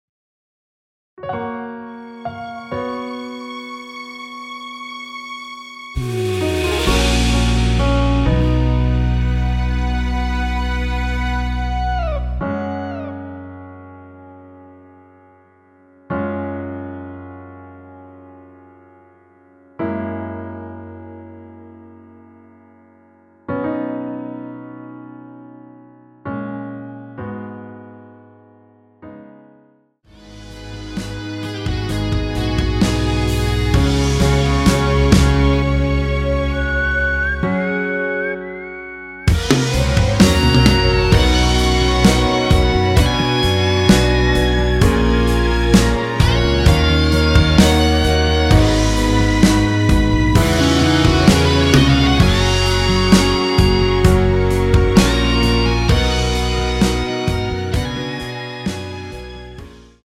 원키에서(+3)올린 MR입니다.
여성분들이 부르실수있는 키 입니다.
F#
앞부분30초, 뒷부분30초씩 편집해서 올려 드리고 있습니다.